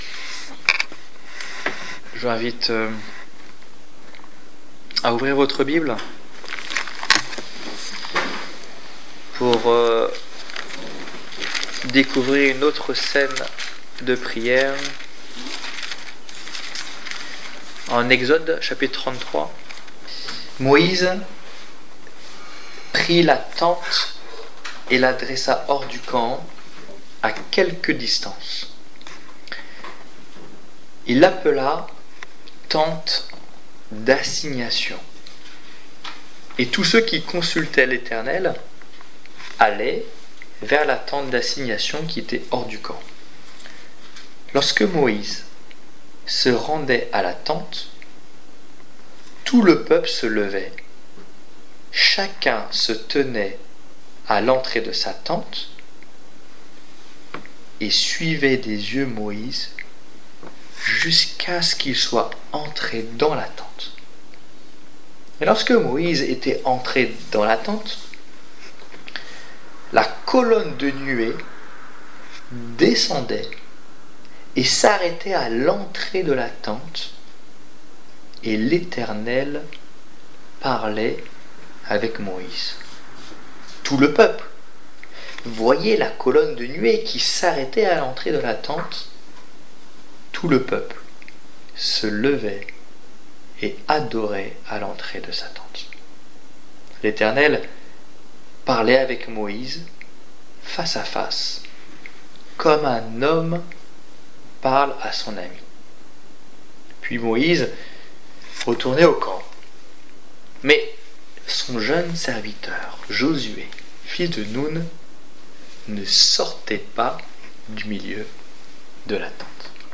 Étude biblique du 3 février 2016